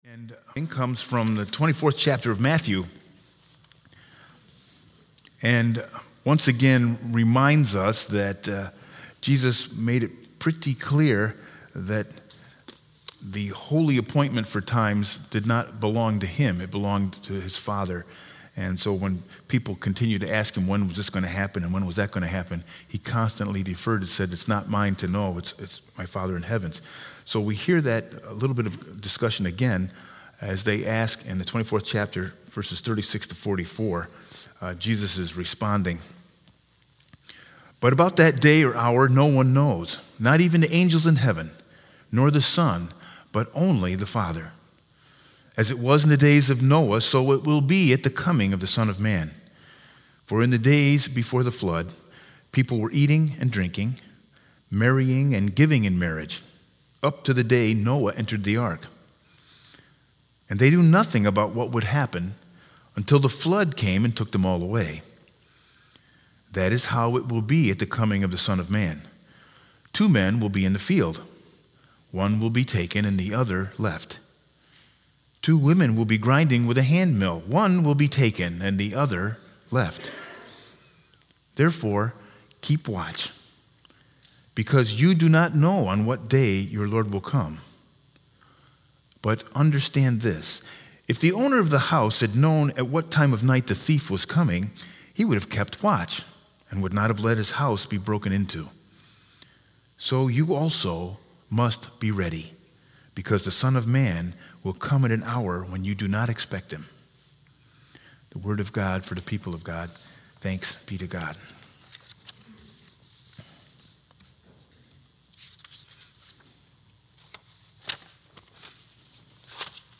Sermon 12-1-19 with Scripture Lesson Matthew 24_36-44